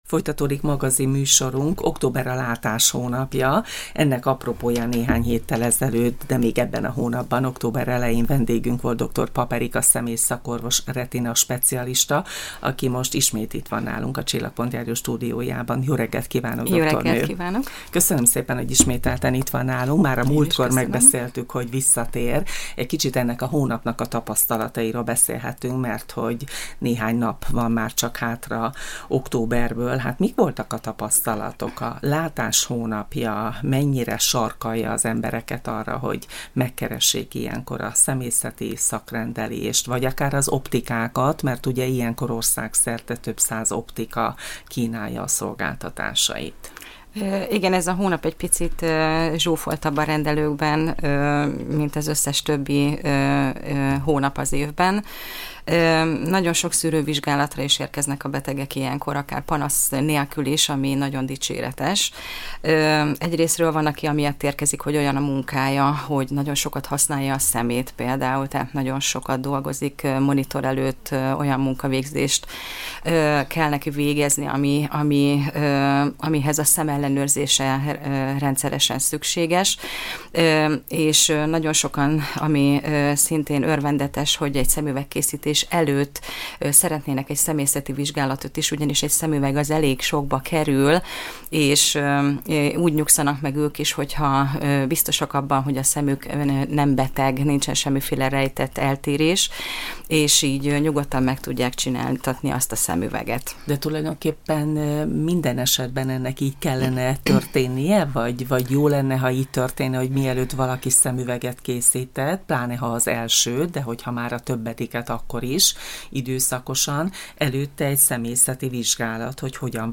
A leggyakoribb időskori szembetegségről kérdeztük szakértő vendégünket.